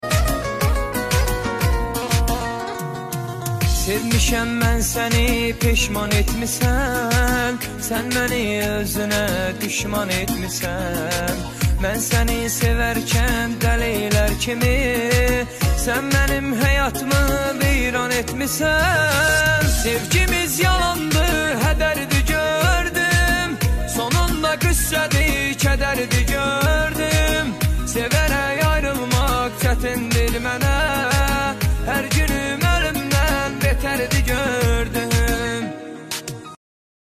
Qəmli mahnılar 😌